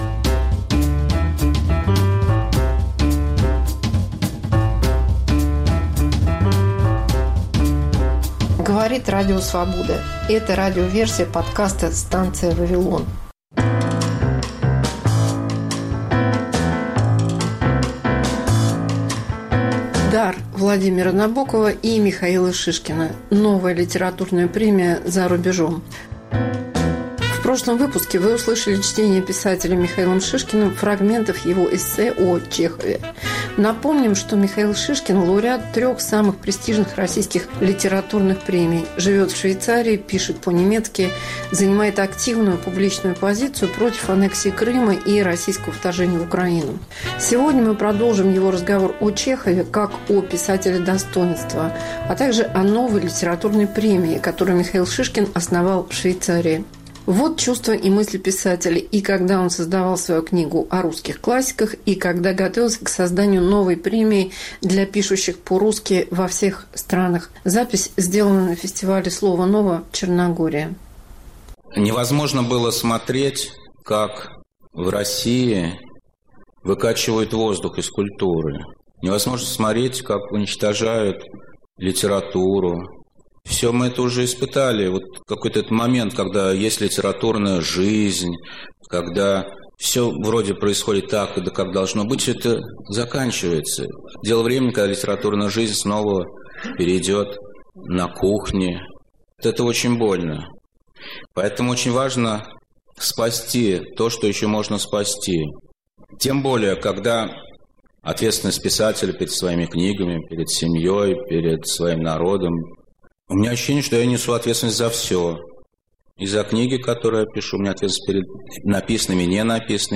Елена Фанайлова в политическом кабаре эпохи инстаграма. Мегаполис Москва как Радио Вавилон: современный звук, неожиданные сюжеты, разные голоса